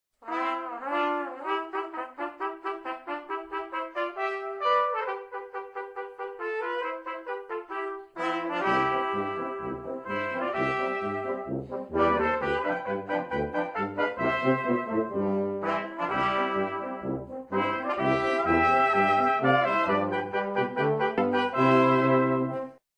Einstimmiger Chorgesang zu 86 deutschen Volksliedern.
Probenmitschnitt